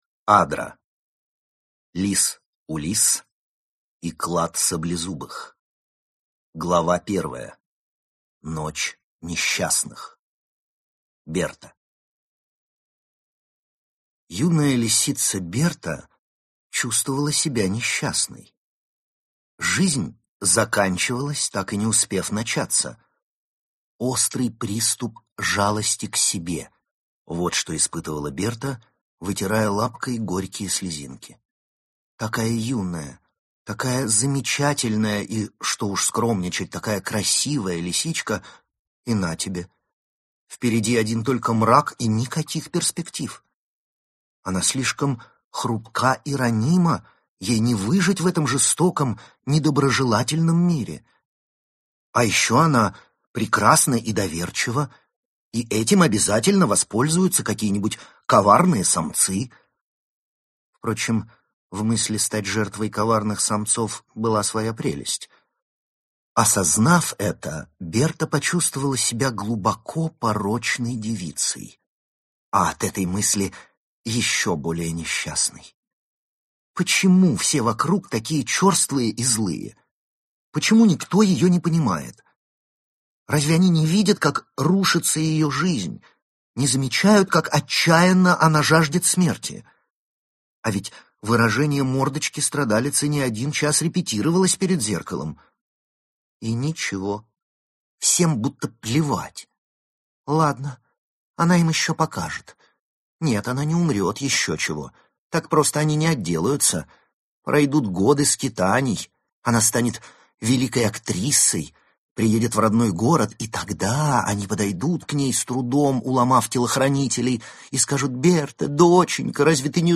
Аудиокнига Лис Улисс и клад саблезубых | Библиотека аудиокниг